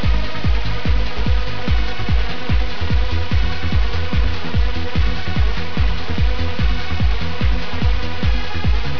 live in Italy 2003